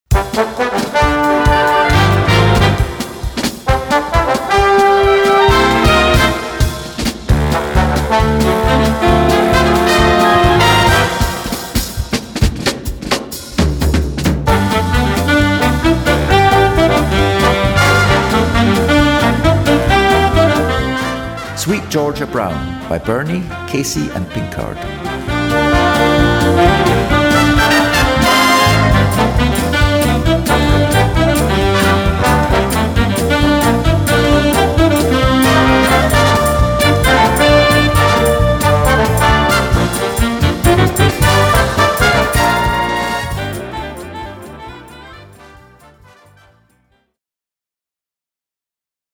Gattung: Konzertante Unterhaltungsmusik
Besetzung: Blasorchester